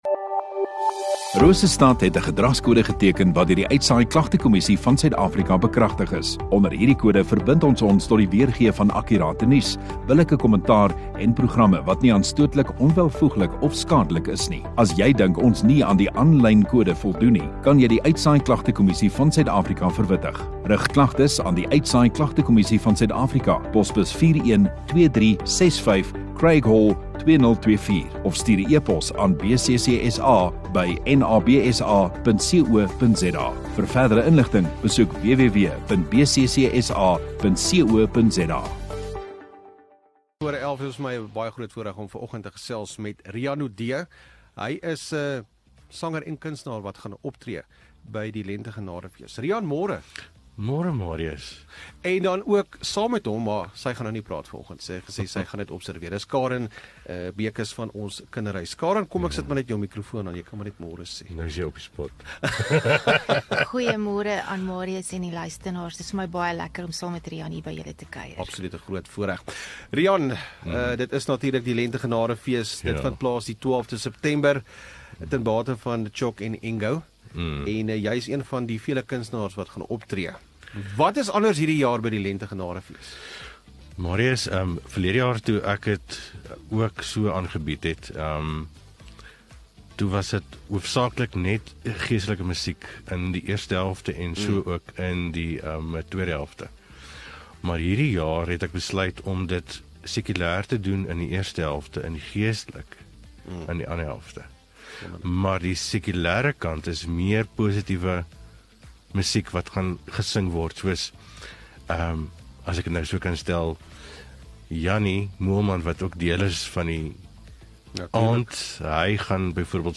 Radio Rosestad View Promo Continue Radio Rosestad Install Kunstenaar Onderhoude 26 Aug Lente Genade Fees